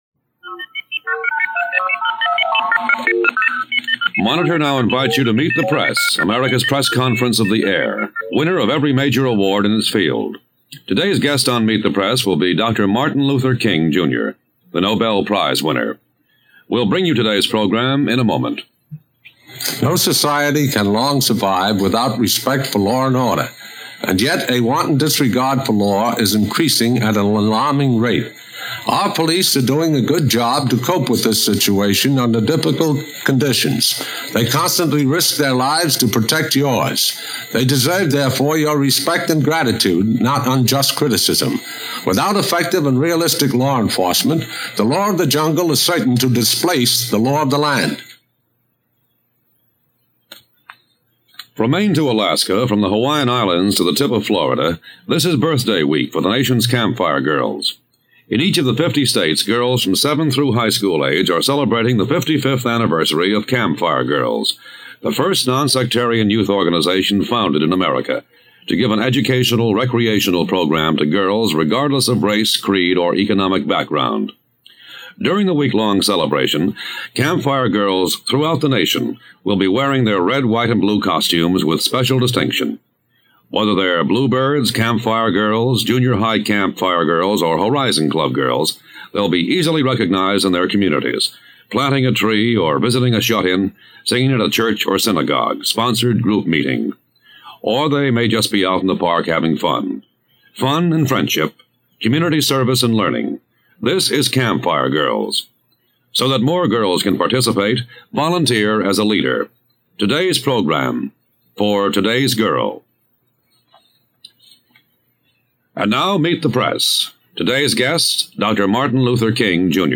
Monitor was an American weekend radio program broadcast live and nationwide on the NBC Radio Network from June 12, 1955, until January 26, 1975.
On March 28, 1965, Dr. King appeared on Meet the Press to discuss the historic Selma to Montgomery marches, which had taken place earlier that month. During the interview, he addressed the importance of the Voting Rights Act, the challenges of nonviolent resistance, and the ongoing struggle for civil rights.